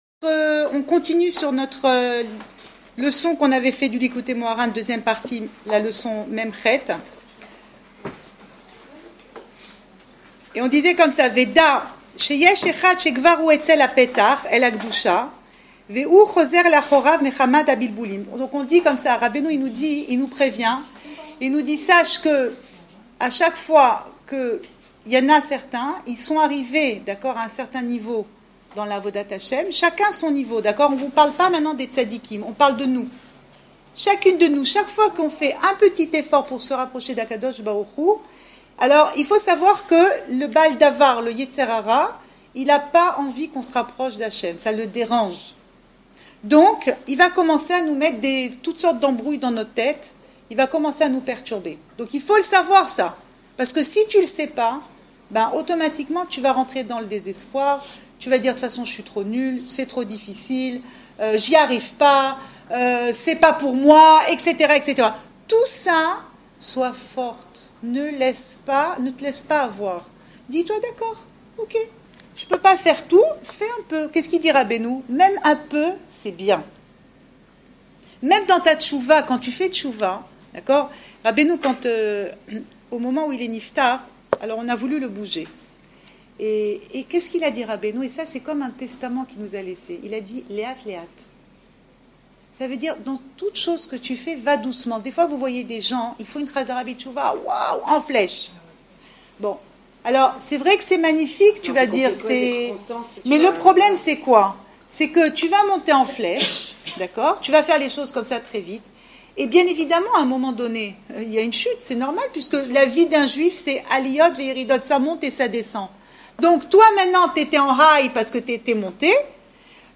Cours audio Le coin des femmes Pensée Breslev Vie de couple - 15 novembre 2016 1 janvier 2017 Le Baal Davar ne veut pas que l’on se rapproche d’Hachem ! Enregistré à Raanana